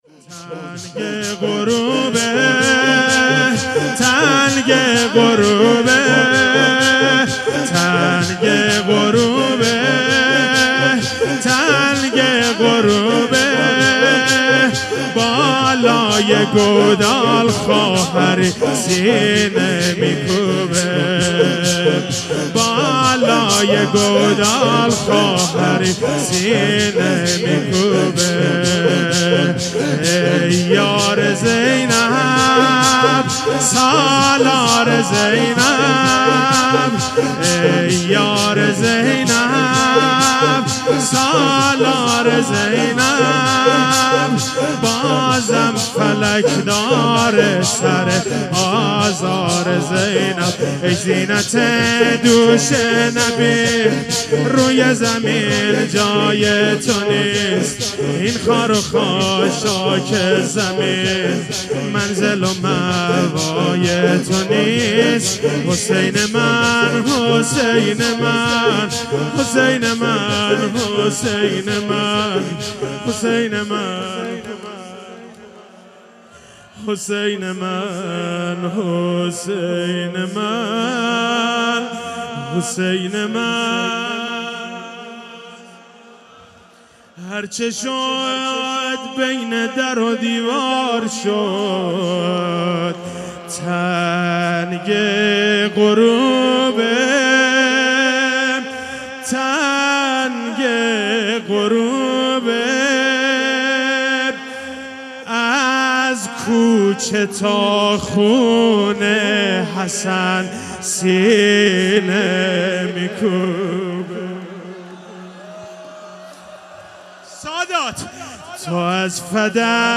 محرم1400 - شب چهارم - روضه - تنگ غروبه